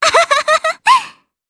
Kirze-Vox_Happy3_jp.wav